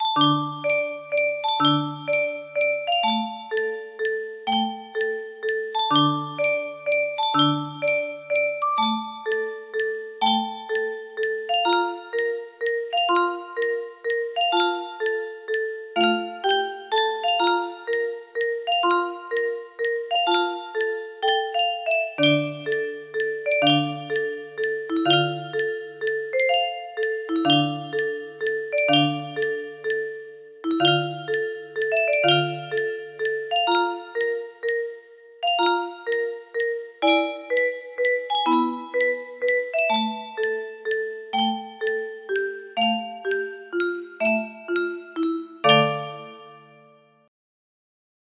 fever dream music box melody